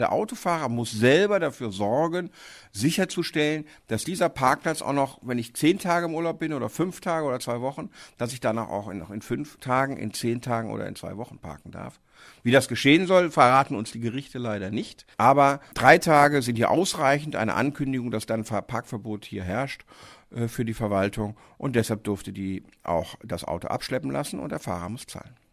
O-Ton: Abgeschleppt im Urlaub? Halter muss trotzdem zahlen!
DAV, O-Töne / Radiobeiträge, Ratgeber, Recht, , , ,